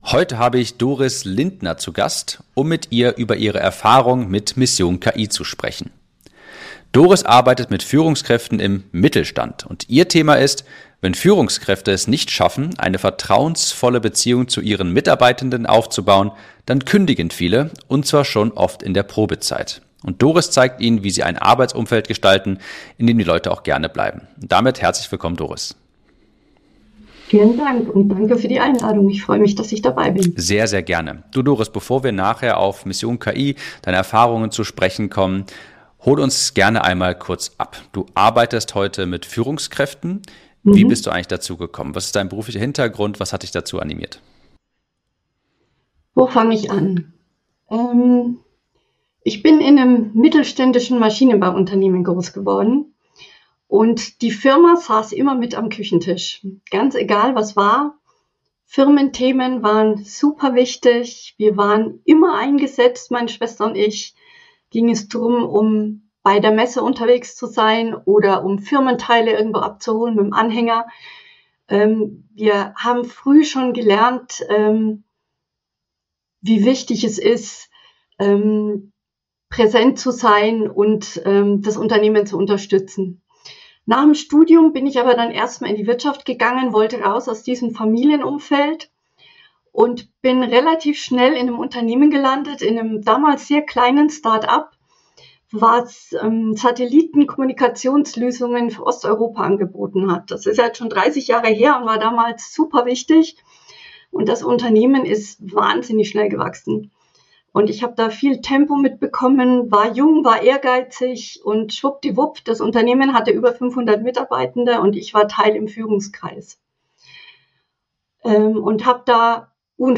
Interview ~ Conversions und Copywriting Podcast